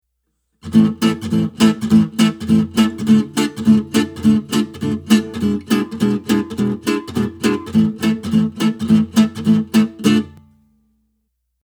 On beats 2 and 4, I like to hit all strings, but a few players like to alternate between bass (roughly E to G) and treble strings (roughly D to E) on 1 and 3.
All strings on 2 and 4 :